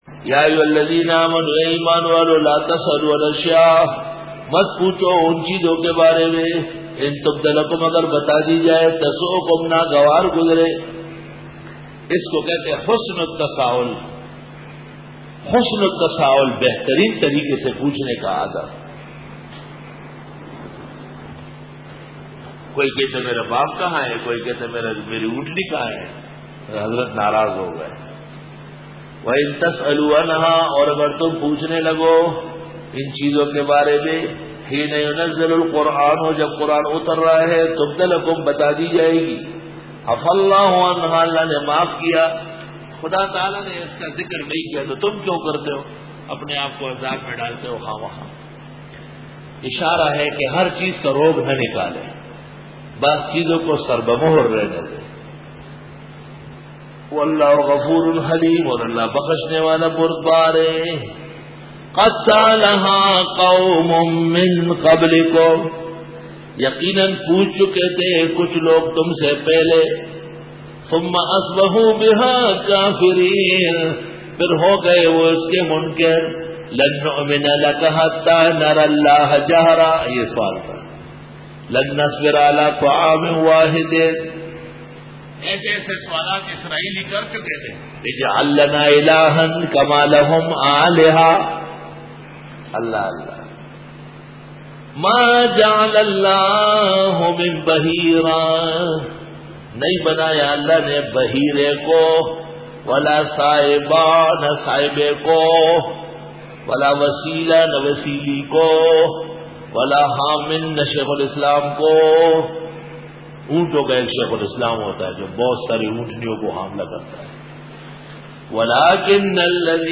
Dora-e-Tafseer 2006